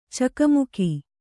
♪ cakamuki